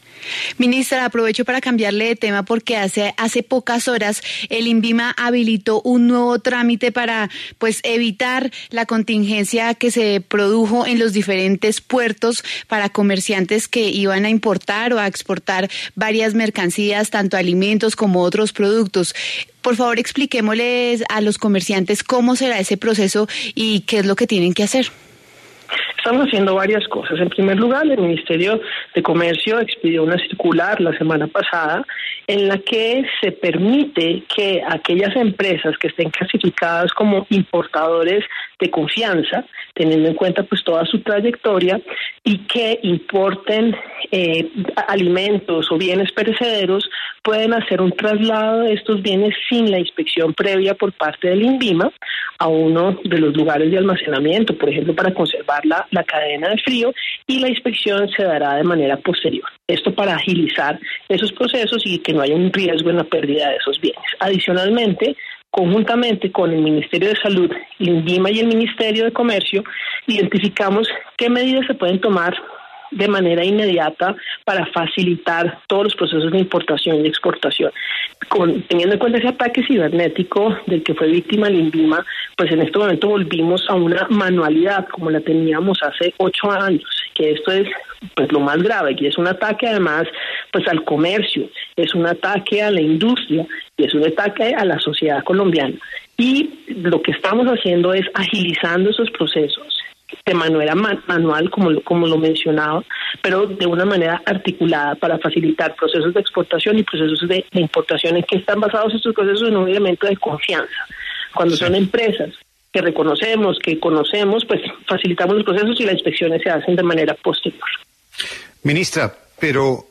Lo invitamos a que escuche la entrevista completa a la ministra de Comercio, María Ximena Lombana, en La W: